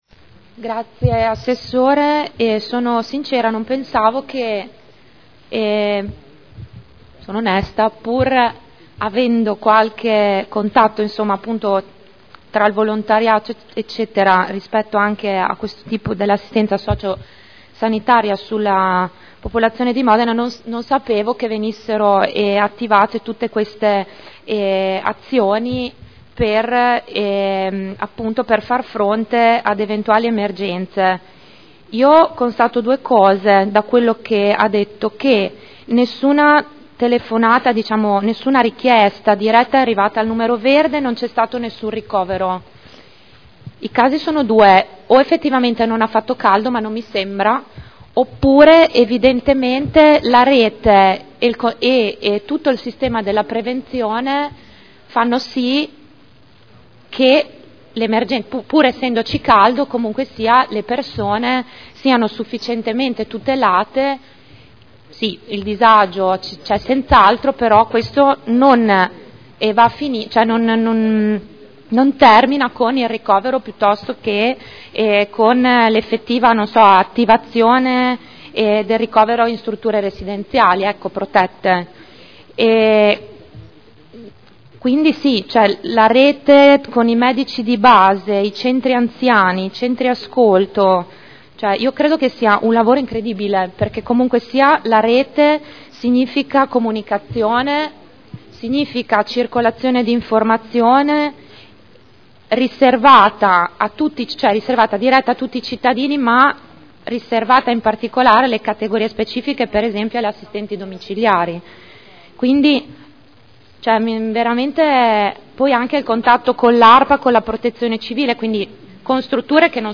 Elisa Sala — Sito Audio Consiglio Comunale